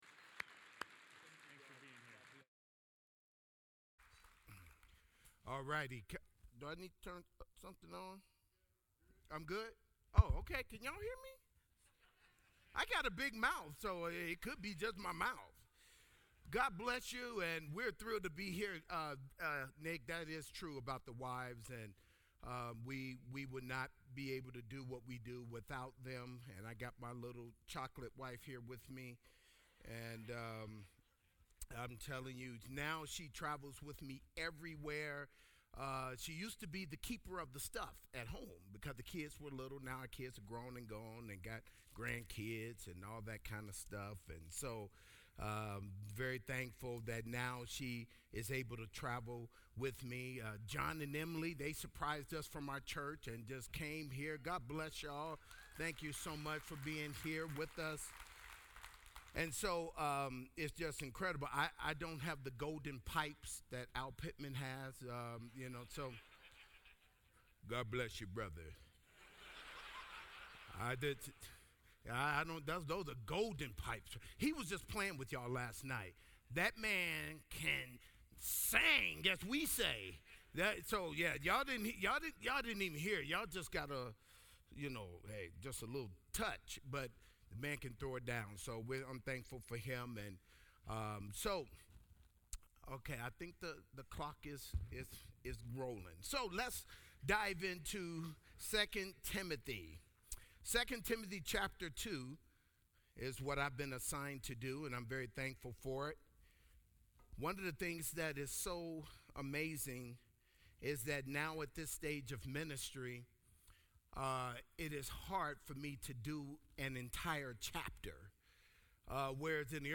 Southwest Pastors and Leaders Conference 2019